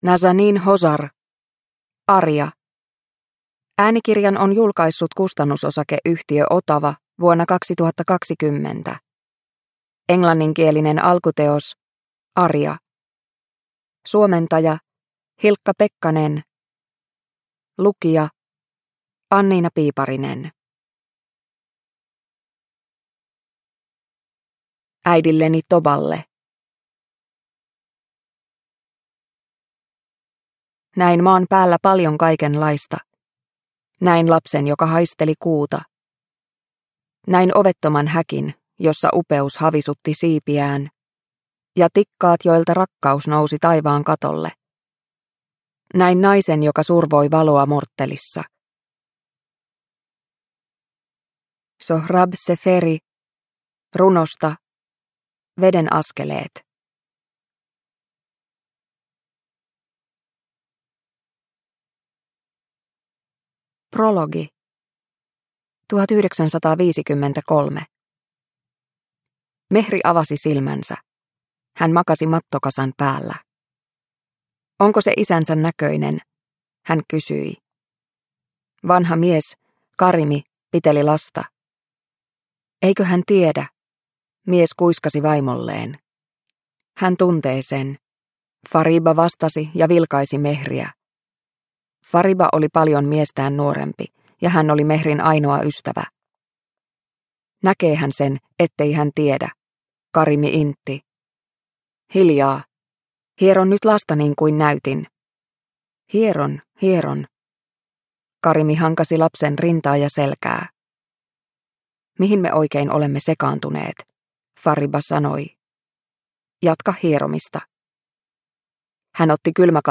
Aria – Ljudbok – Laddas ner